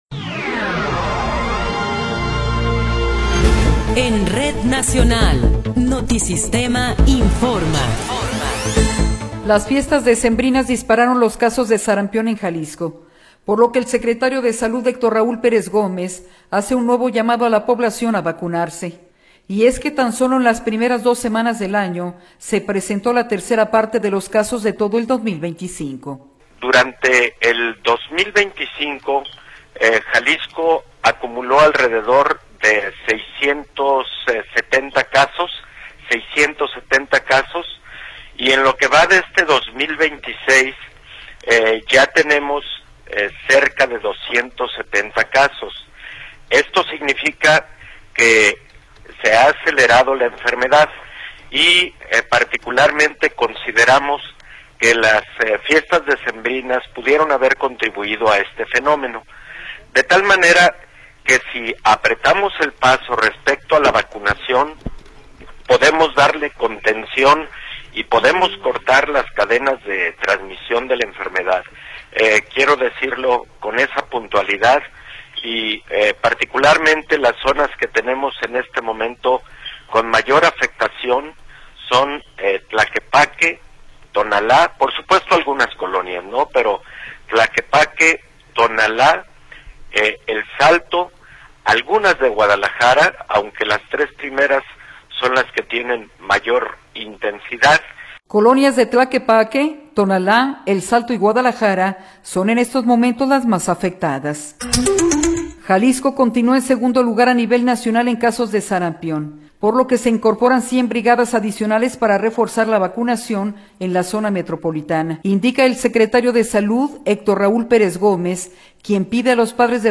Noticiero 15 hrs. – 18 de Enero de 2026